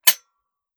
12ga Pump Shotgun - Dry Trigger 001.wav